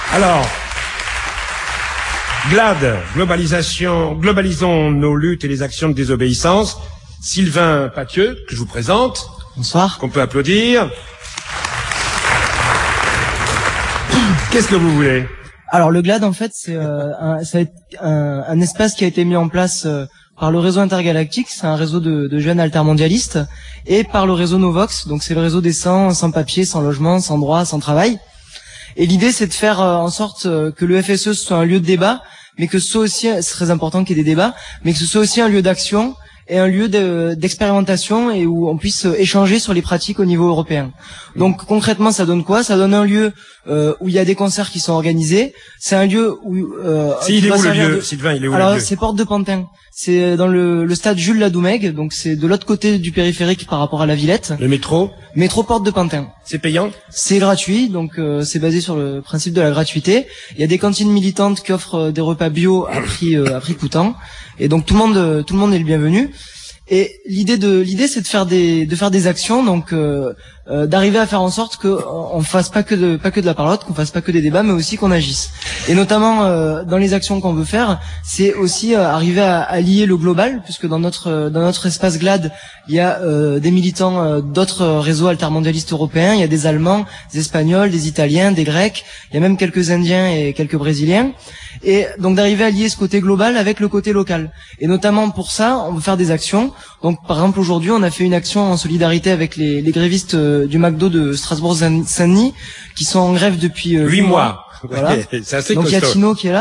Interventions diffusées le 13 novembre 2003 - dans le cadre du Forum Social Européen en Seine-St-Denis - sur le site de l’émission radiophonique Là Bas si j’y suis.